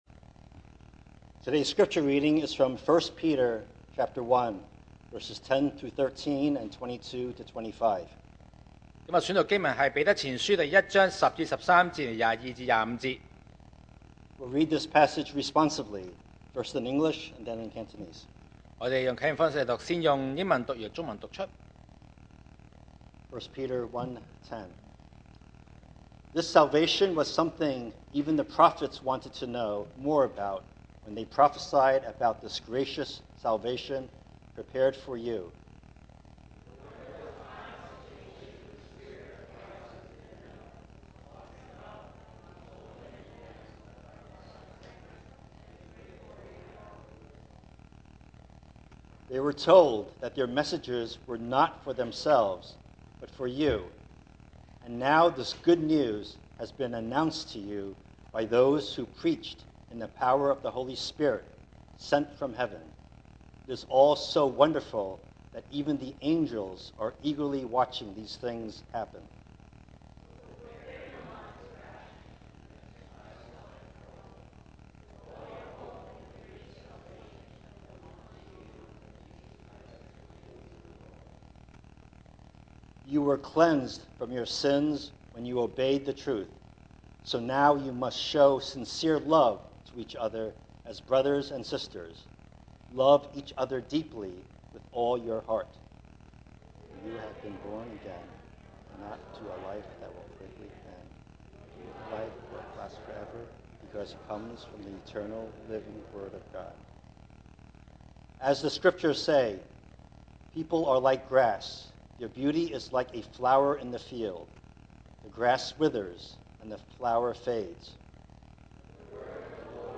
2023 sermon audios
Passage: 1 Peter 1:10-13, 1 Peter 1:22-25 Service Type: Sunday Morning